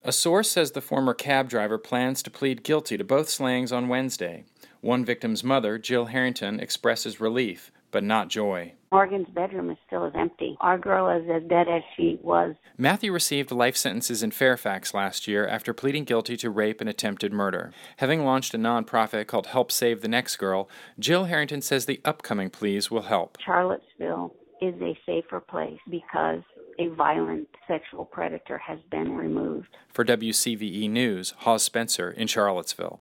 For WCVE News